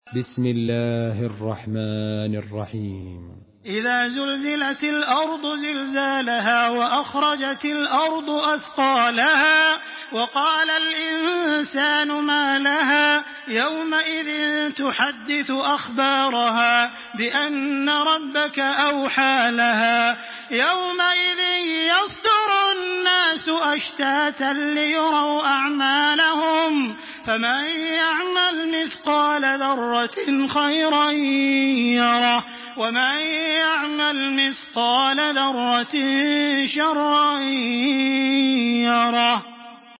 Makkah Taraweeh 1426
Murattal